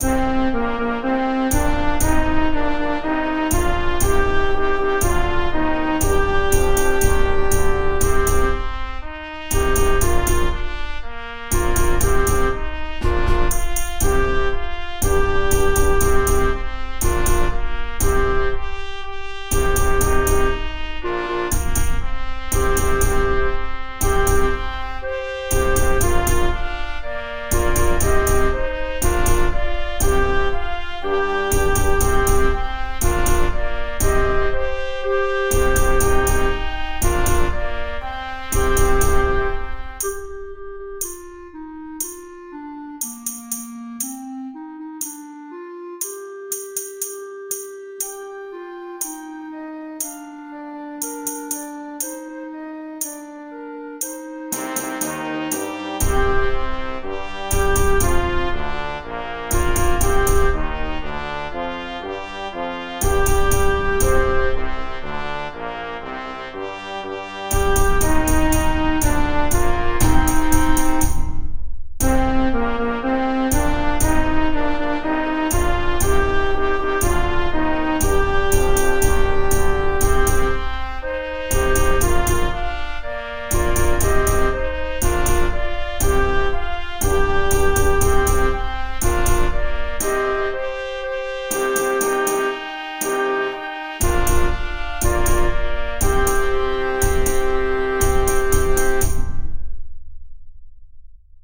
The Lost Kingdom (percussion soundtrack).mp3